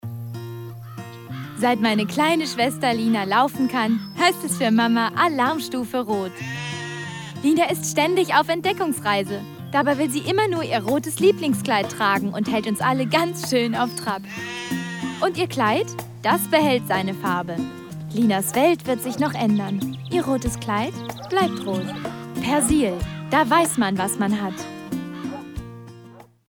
Synchron, Cartoon, Werbung, sehr junge, helle, klare Stimme
Kein Dialekt
Sprechprobe: eLearning (Muttersprache):
My voice is young, high and clear.